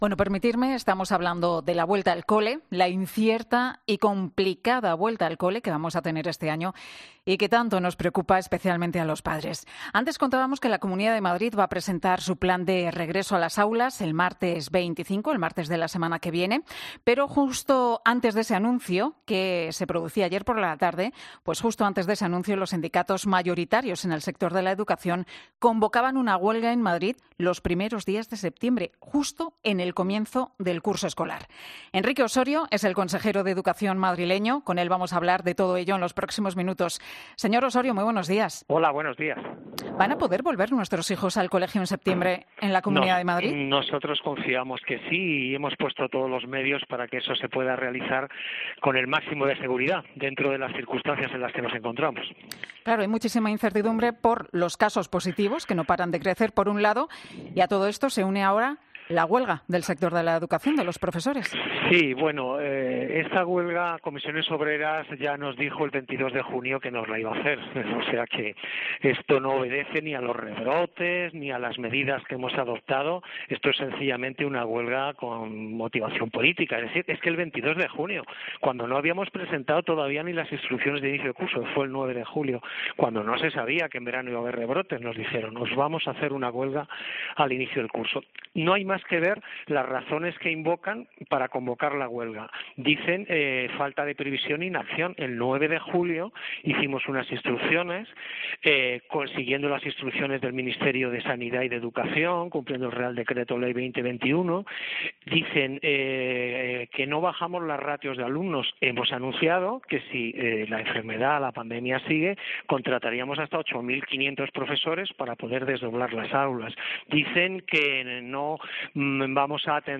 Enrique Ossorio. Consejero de Educación y Juventud de la Comunidad de Madrid en 'Herrera en COPE'